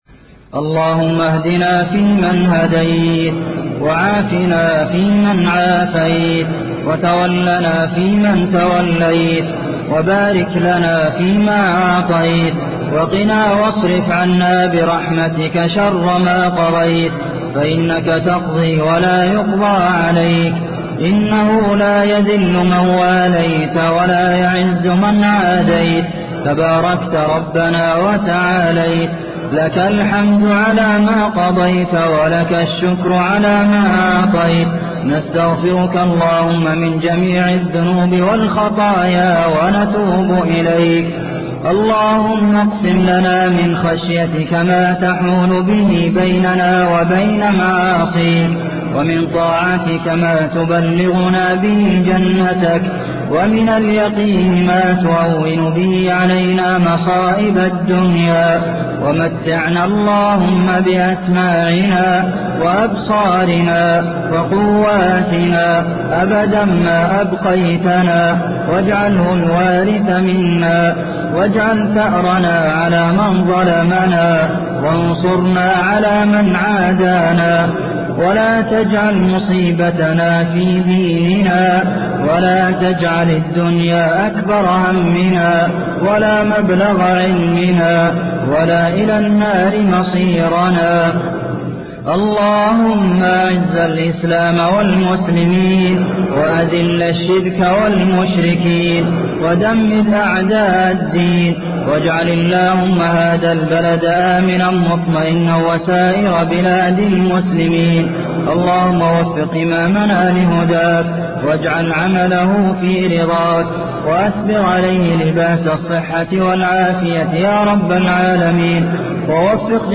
دعاء القنوت بصوت إمام الحرم عبد المحسن القاسم.
تسجيل لدعاء القنوت المؤثر بصوت الشيخ عبد المحسن القاسم من الحرم المدني.